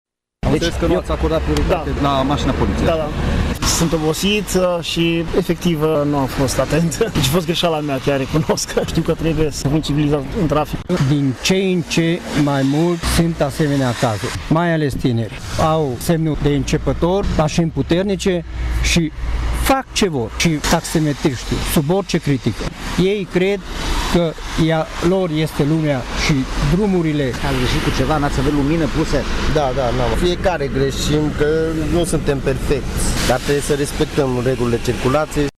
Șoferii trași pe dreapta de echipajele de poliție și-au recunoscut vina. Unii nu au acordat prioritate, nu purtau centura sau nu aveau aprinse farurile la mașini, deși ploua: